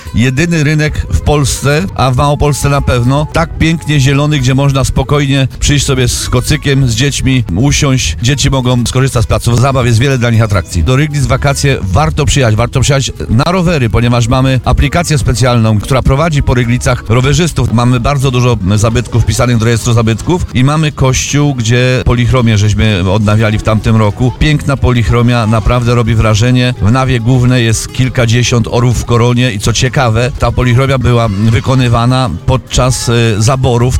Tym razem nasze plenerowe studio zaparkowało na ryglickim rynku. To właśnie Ryglice były kolejnym przystankiem na Wakacyjnej Trasie RDN.